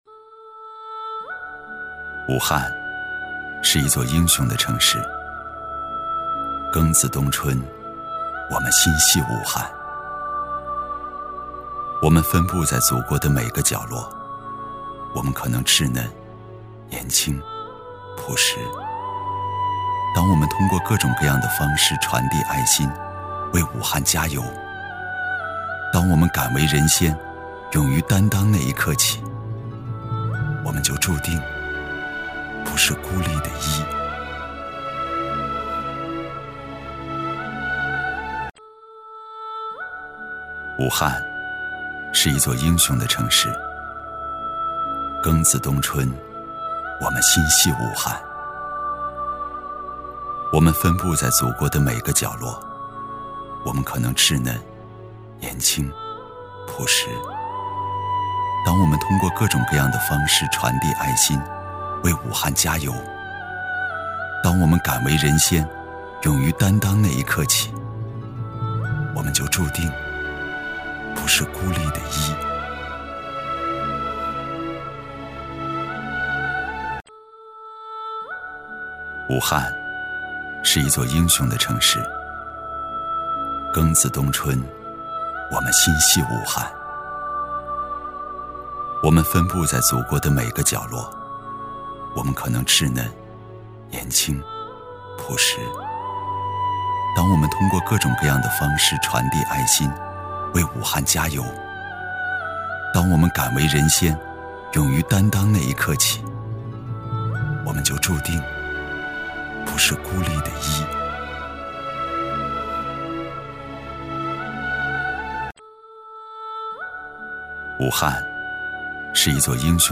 • 男1 国语 男声 独白 微电影旁白 走心武汉 感人煽情|素人